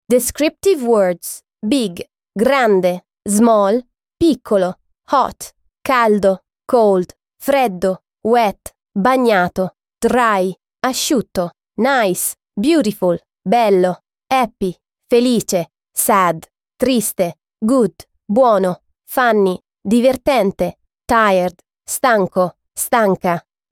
Lesson 7